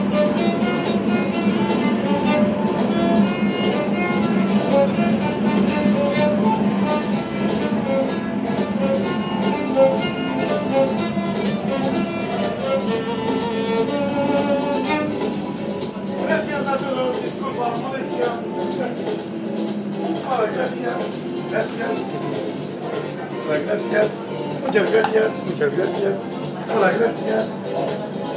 Debe ser difícil tocar el violín en el metro en movimiento